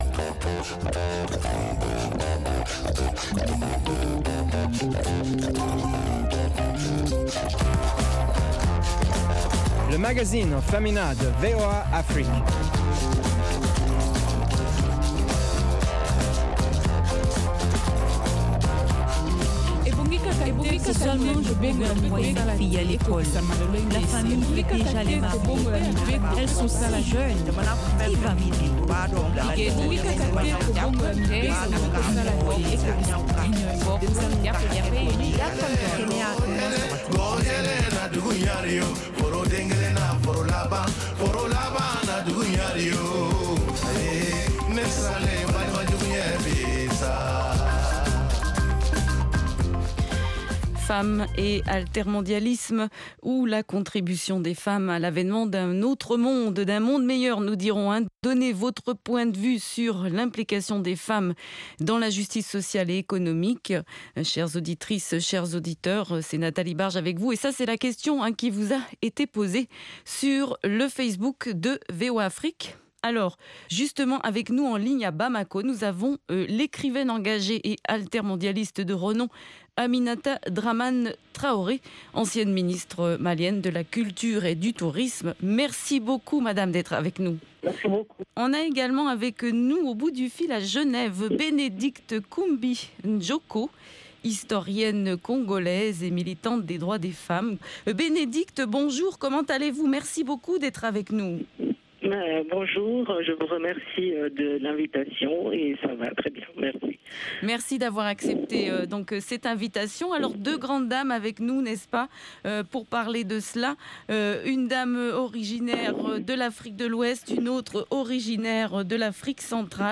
Femmes et altermondialisme, ou la contribution des femmes à l’avènement d’un autre monde. Dans cette édition, un débat sur l’implication des femmes dans la justice sociale et économique